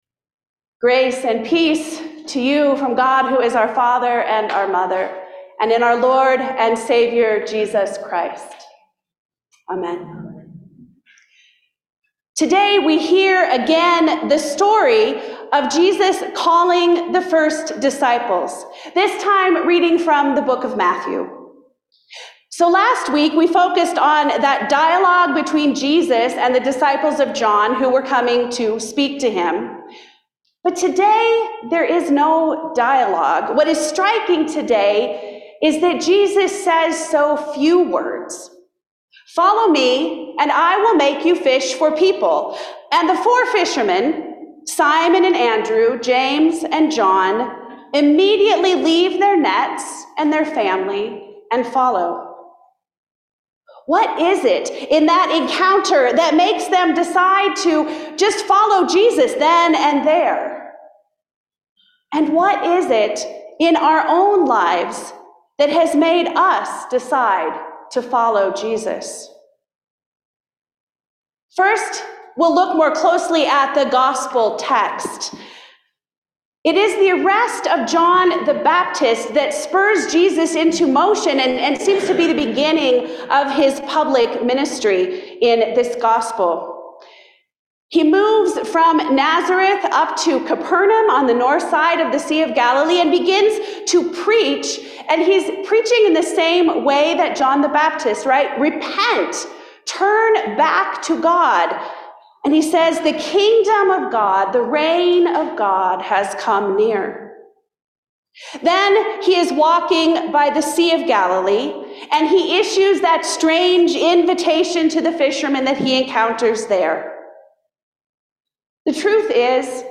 Sermons – Page 32 – All Saints Lutheran Church, ELCA